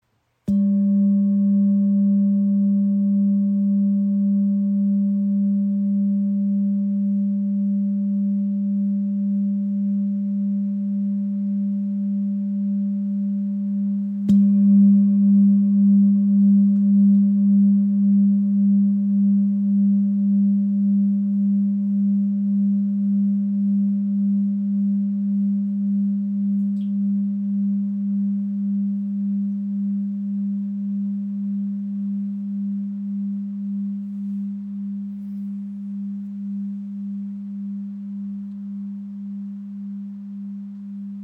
Tibetische Klangschale mit Blume des Lebens | ø 20.5 cm | Ton ~ G
Handgefertigte Klangschale aus Nepal, mit eingravierter Blume des Lebens
• Icon Die Klangschale mit 198,5 Hz liegt nahe am Erdton (194,18 Hz)
• Icon Zentrierender, obertonreicher Klang im Ton ~ G (Halschakra) 198.5 Hz